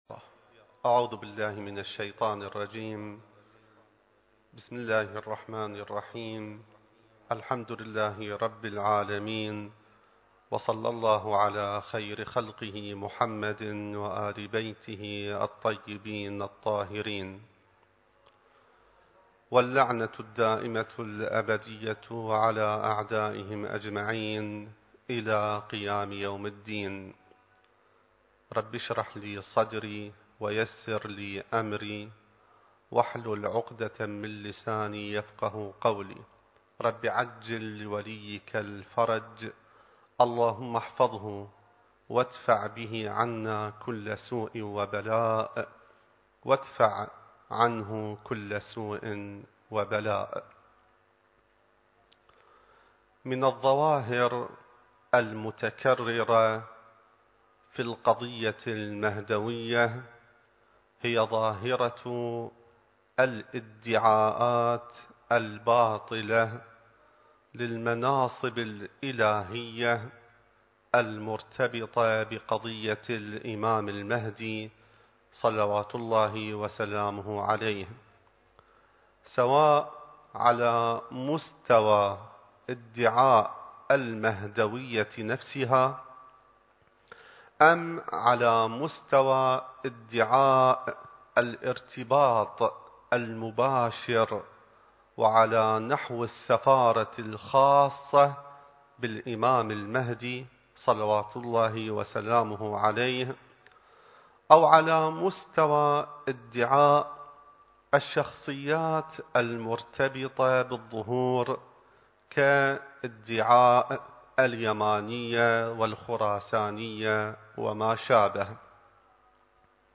المكان: العتبة العلوية المقدسة الزمان: ذكرى ولادة الإمام المهدي (عجّل الله فرجه) التاريخ: 2021